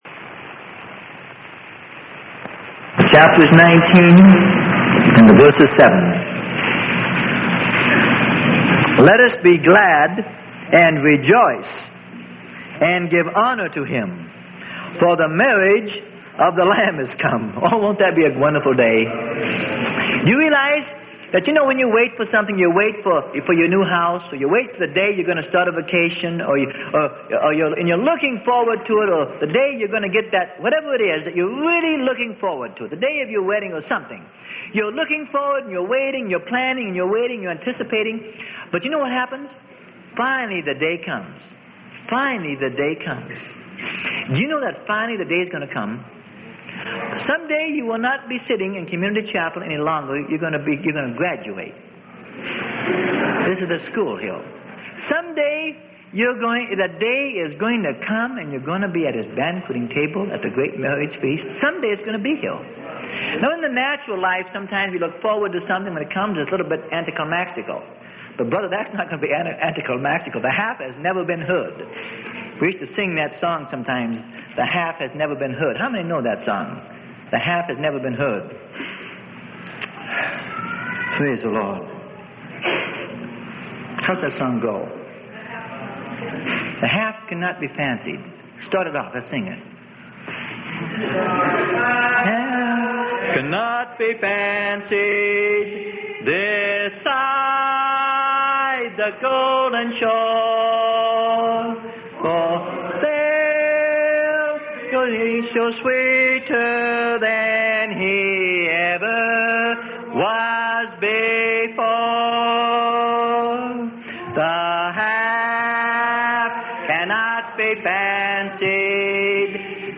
Sermon: Make Ready - Rev 19:7 - Freely Given Online Library